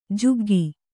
♪ juggi